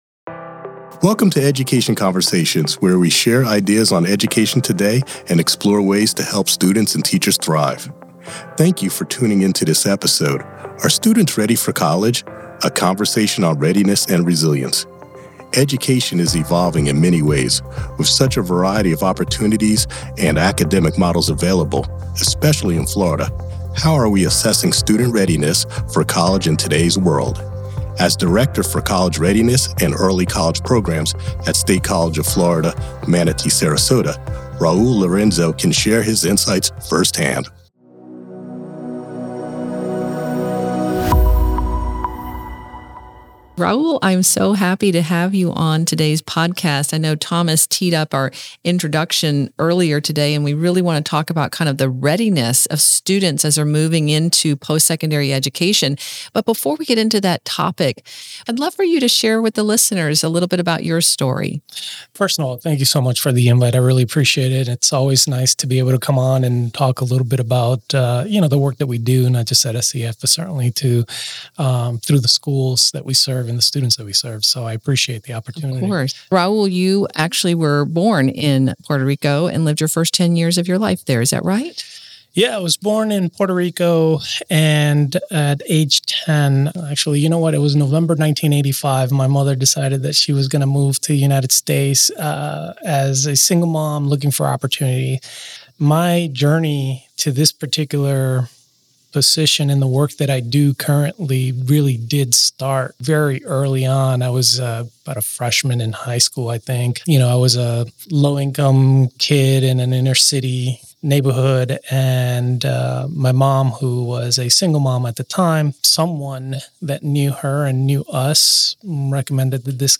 S3E7 Are Students Ready for College? A Conversation on Readiness and Resilience